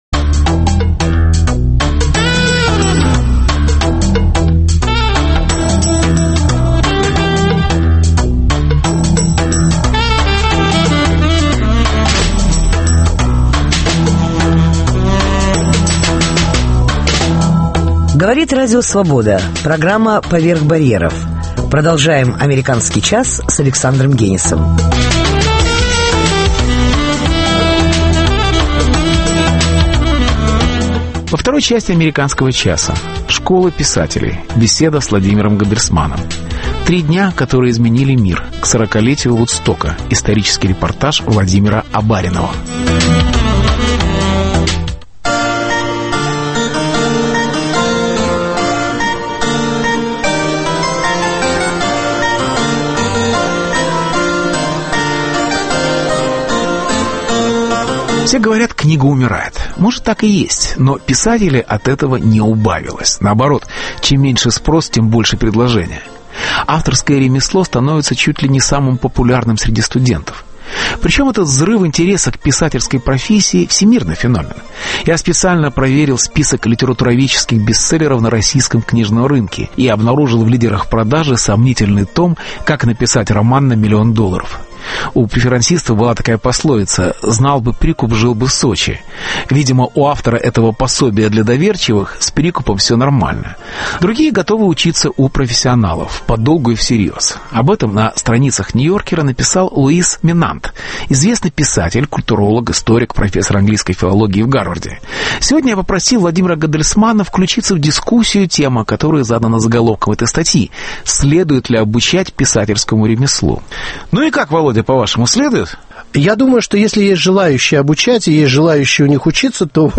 Школа писателей - беседа с Владимиром Гандельсманом